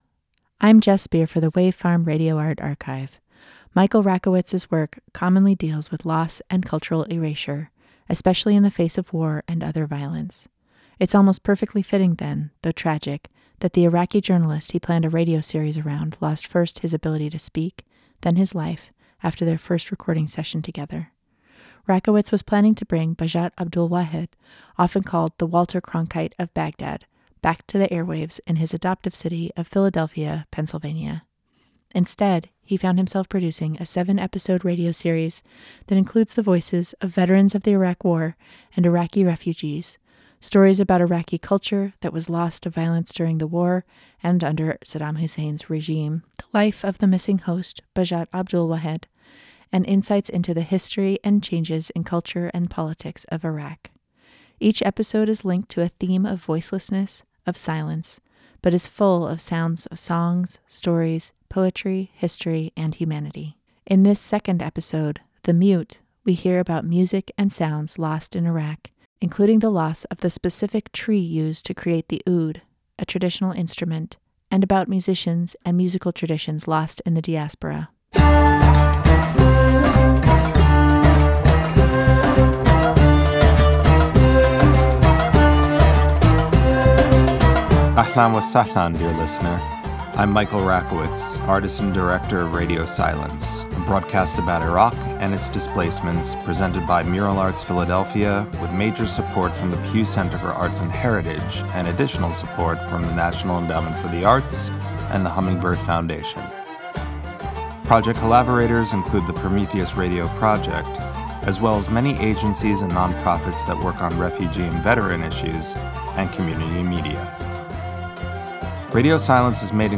Each episode is linked to a theme of voicelessness, of silence, but is full of sounds of songs, stories, poetry, history, and humanity. As with his other artworks, Rakowitz recruits participants to engage in the art, featuring writing by Iraq war veterans, a radio play by an Iraqi artist, Iraqi music, and interviews with historians, journalists, and everyday people. These sounds weave together different elements of the story of Iraq in the 20th century, sketching out a picture of what was lost, and what is being created both in Iraq and in the diaspora created by decades of colonialism and conflict in Iraq.